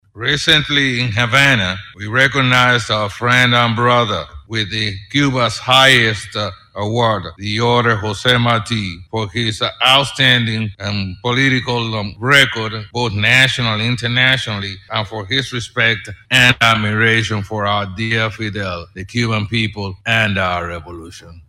The Cuban President made the remark during a Special sitting of Parliament yesterday.
Speaking through an Interpreter, President Miguel Diaz-Canel reminded Members of Parliament that Dr. Gonsalves received Cuba’s highest award for his respect and admiration for the late Cuban President and for his outstanding political performance.